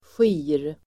Ladda ner uttalet
Uttal: [sji:r]